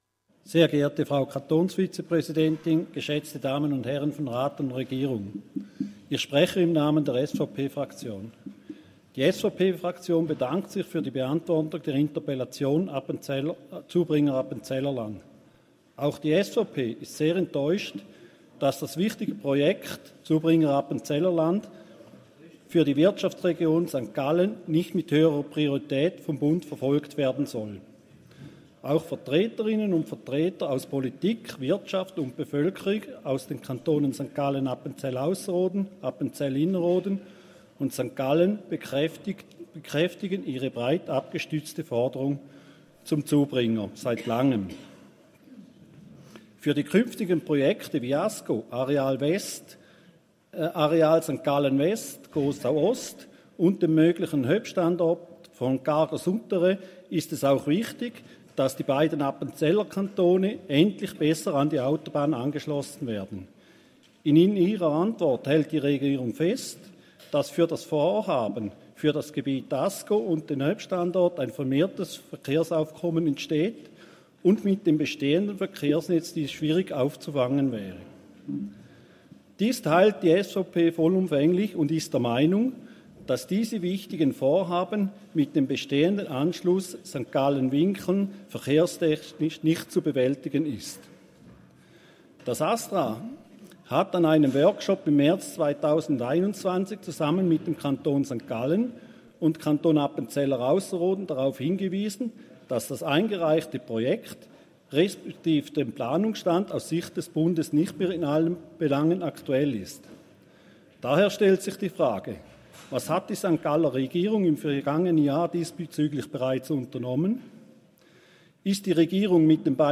20.9.2022Wortmeldung
Session des Kantonsrates vom 19. bis 21. September 2022